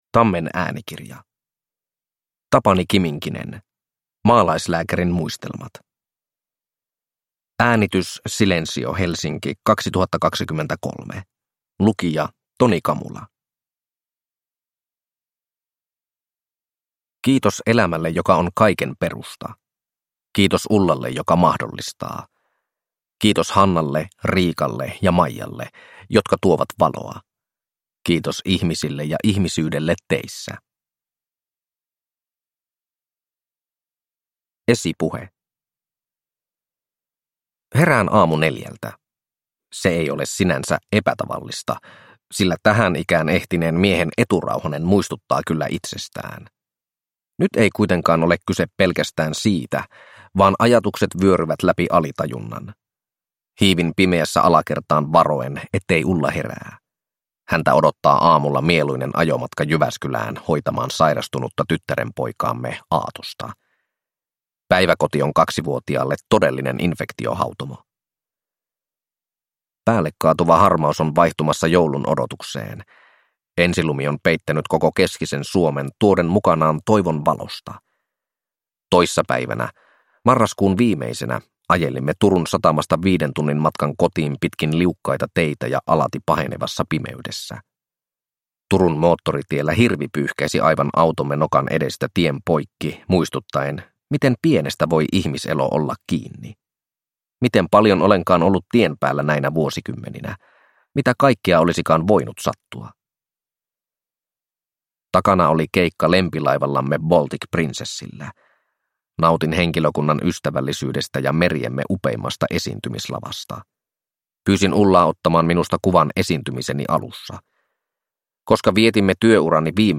Maalaislääkärin muistelmat – Ljudbok – Laddas ner